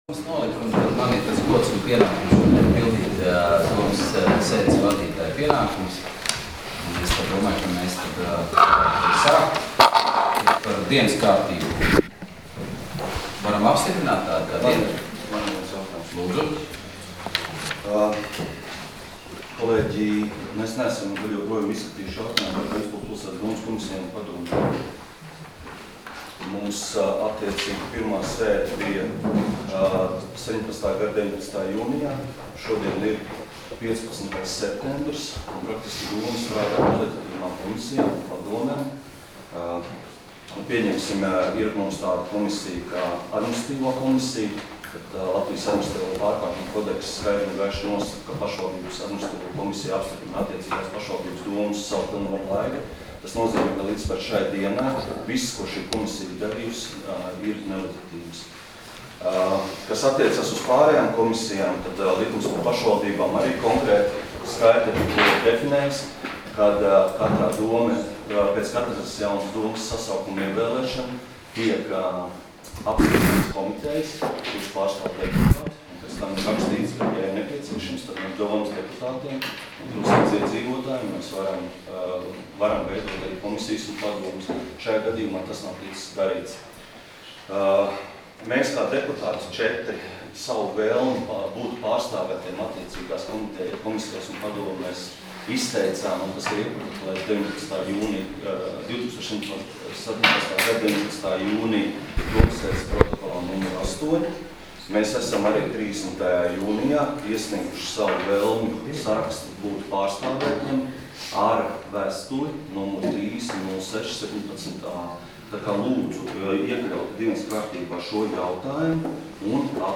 Domes sēdes 15.09.2017. audioieraksts